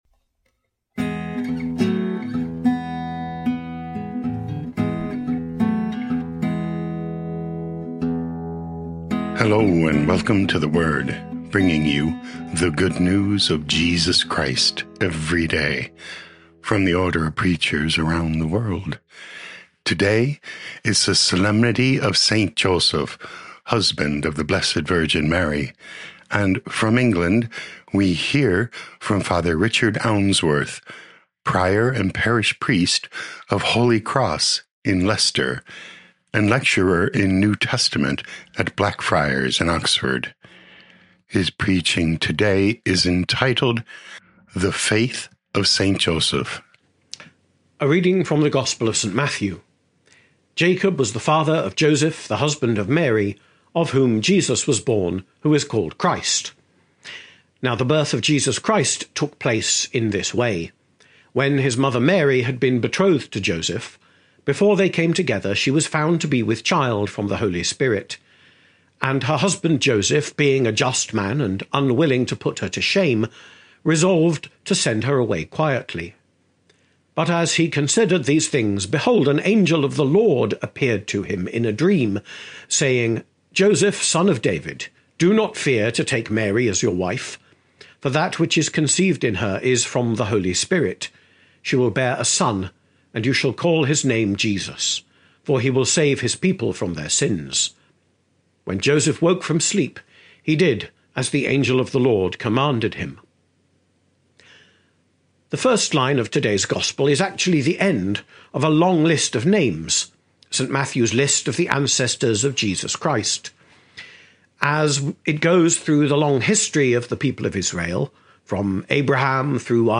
19 Mar 2026 St. Joseph’s Faith Podcast: Play in new window | Download For 19 March 2026, The Solemnity of Saint Joseph, husband of the Blessed Virgin Mary, based on Matthew 1:16, 8-21,24a, sent in from Leicester, England, UK.